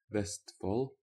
Vestfold (pronounced [ˈvɛ̂stfɔɫ]
Vestfold.ogg.mp3